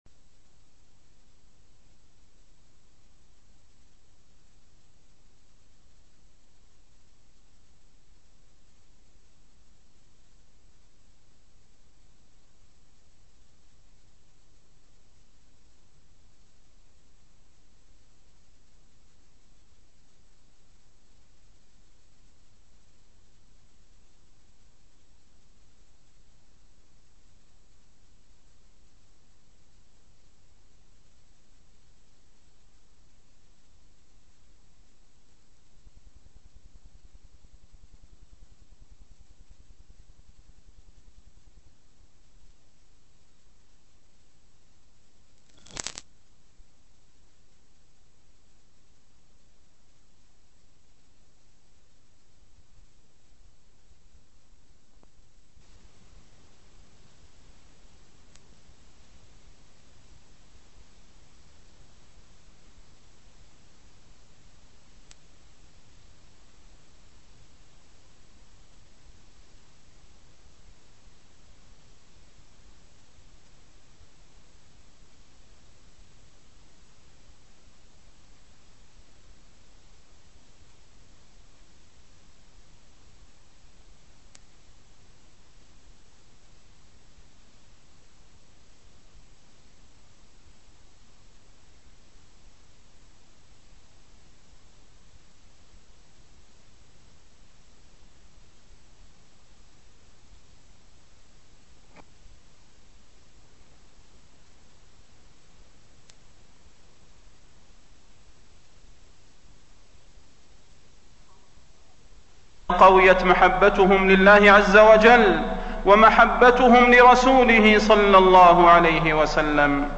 تاريخ النشر ١٣ شوال ١٤٣٠ هـ المكان: المسجد النبوي الشيخ: فضيلة الشيخ د. صلاح بن محمد البدير فضيلة الشيخ د. صلاح بن محمد البدير وجوب المداومة على الطاعات